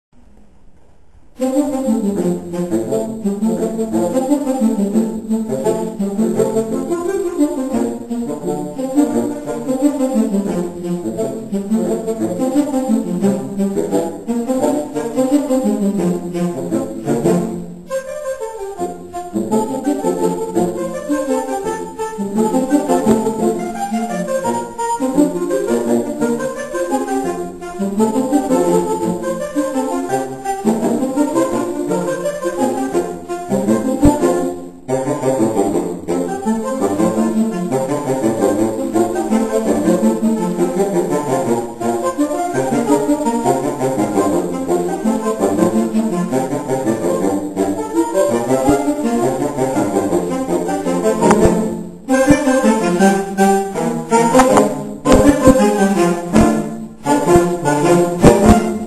Saxofonové kvarteto Moravia
26. září 2006 - 23. komorní koncert na radnici - Podrobný program koncertu "Melodie dýchajícího kovu"
sopránový saxofon
altový saxofon
tenorový saxofon
barytonový saxofon
Ukázkové amatérské nahrávky WMA: